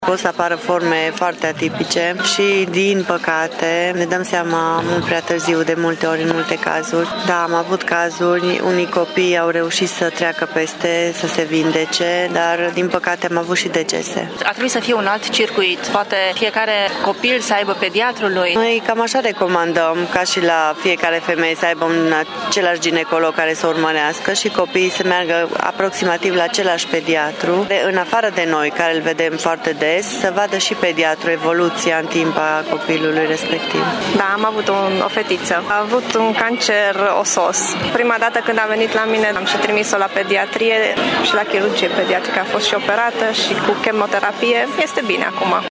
Medicii de familie, participanți la această conferință, au avut cel puțin un copil pacient cu cancer. Aceștia spun că circuitul de diagnostic ar trebui să fie mai bine pus la punct: